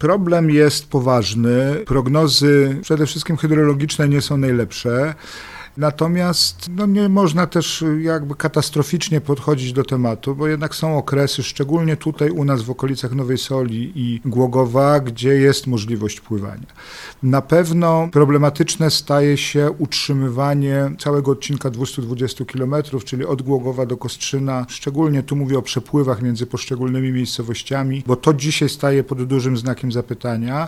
– Sytuacja powtarza się od kilku lat, dlatego dla nas jest to problem, który musimy rozwiązać – powiedział Jacek Milewski wiceprezydent Nowej Soli, która jest liderem odrzańskiego projektu: